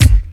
• 90s Hip-Hop Bass Drum Sound G Key 312.wav
Royality free kick drum single shot tuned to the G note. Loudest frequency: 802Hz